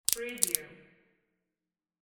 Tree branch cracking sound effect .wav #2
Description: The sound of a small tree branch cracking
Properties: 48.000 kHz 16-bit Stereo
Keywords: tree, branch, wood, crack, cracking, break, breaking
tree-branch-cracking-preview-2.mp3